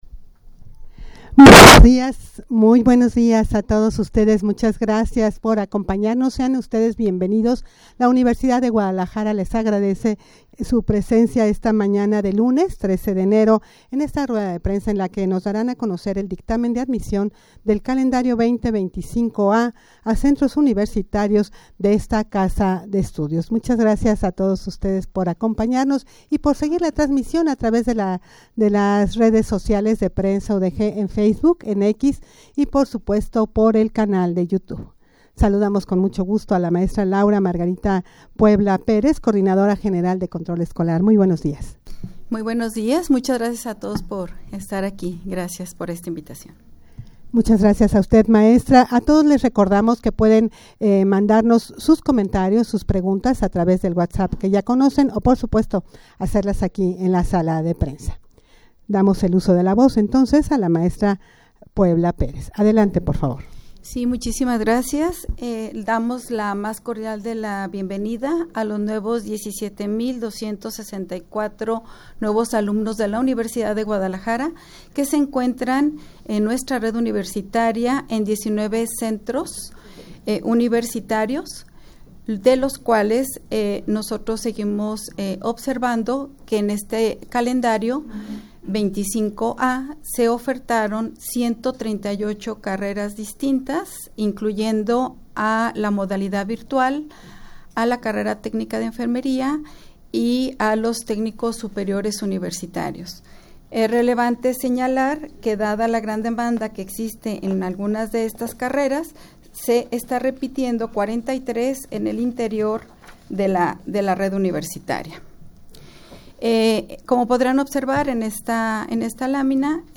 Audio de la Rueda de Prensa
rueda-de-prensa-para-dar-a-conocer-el-dictamen-de-admision-del-calendario-2025-a.mp3